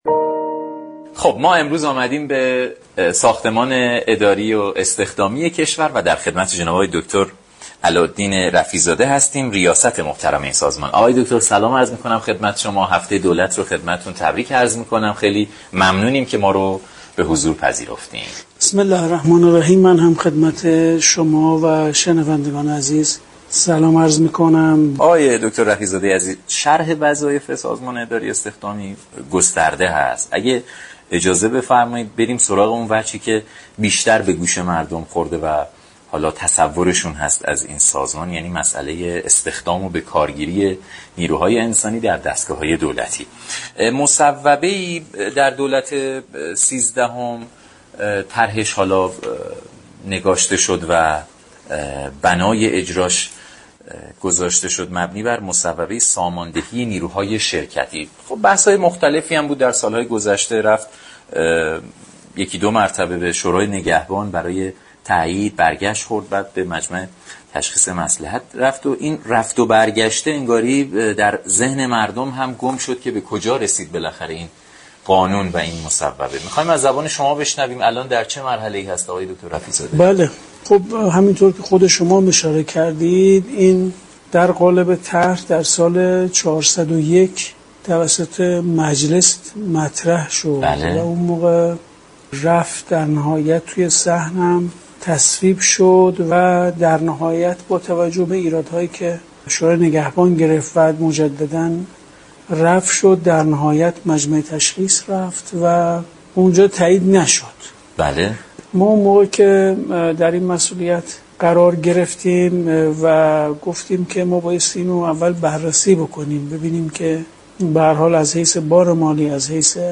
رئیس سازمان اداری و استخدامی كشور در برنامه برای ایران گفت: كمیته بررسی ساماندهی نیروی شركتی در یكی از سناریوها برای فارغ التحصیلان دانشگاه قرارداد معین تعیین و برای غیردانشگاهی قراردا كارگری لحاظ كرد.